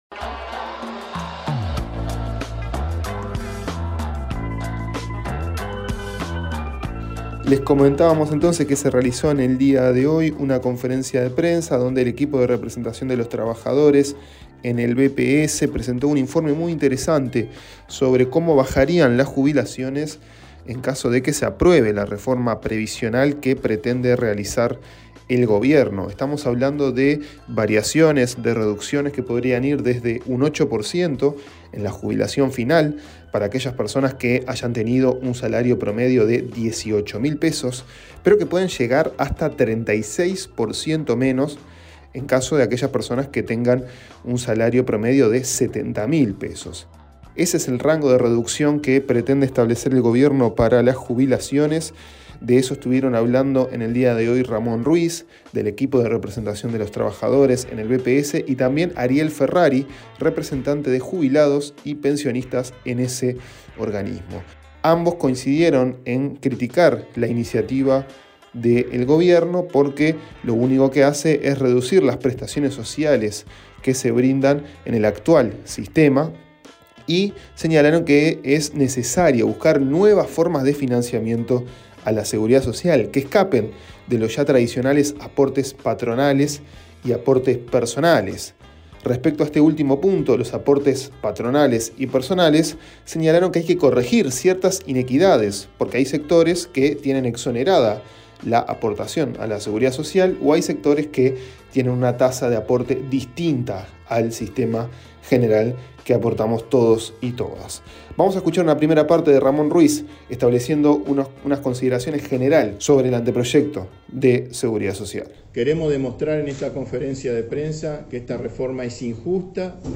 Nos comunicamos con Ramón Ruiz, director del BPS en representación de los trabajadores, para que nos de sus impresiones sobre cómo se transita este momento del proyecto de reforma jubilatoria.